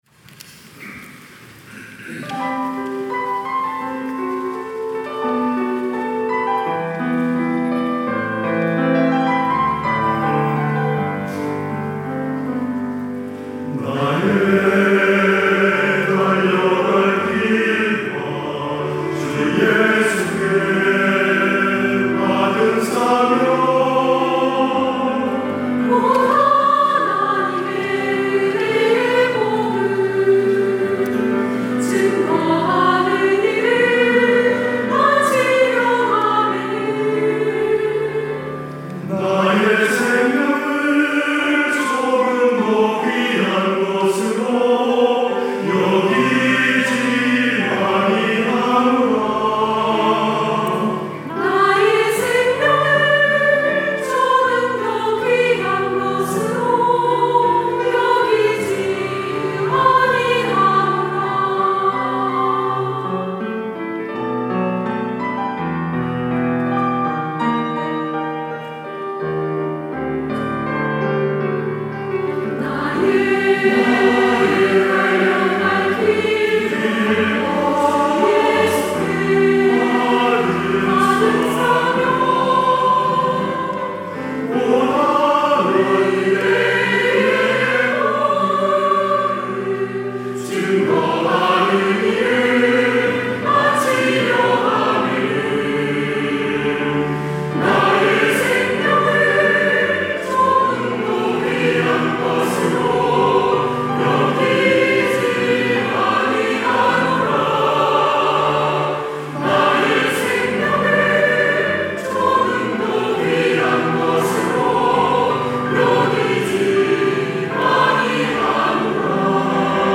할렐루야(주일2부) - 나의 생명을
찬양대